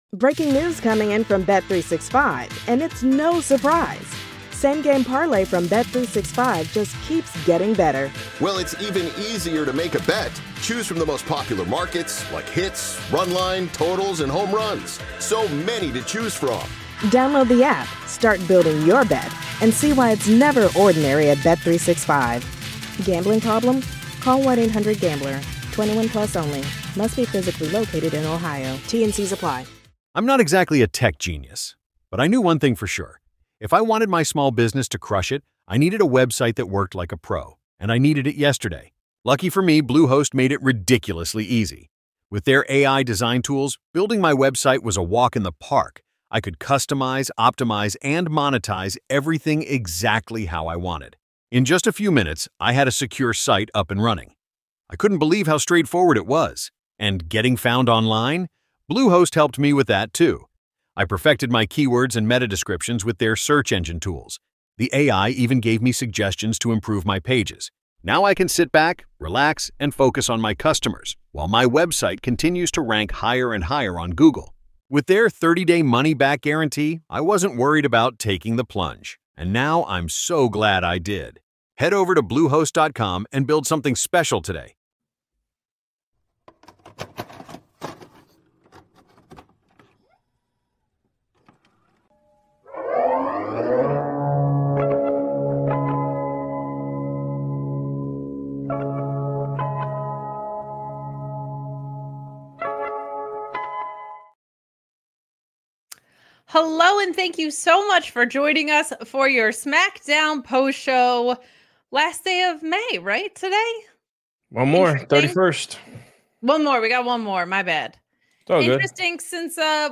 It's an interview with Danhausen.